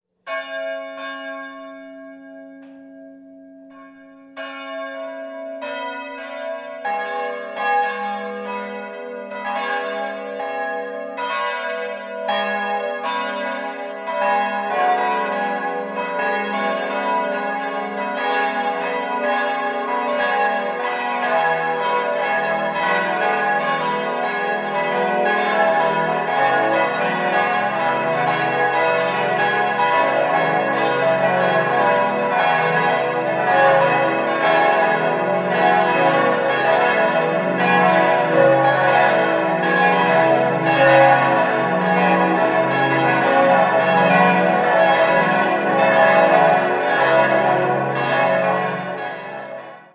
6-stimmiges ausgefülltes und erweitertes Cis-Moll-Geläute: cis'-e'-fis'-gis'-h'-cis''
Das drittgrößte Geläute in Ingolstadt erfreut den Zuhörer mit einem festlichen, edlen Klang.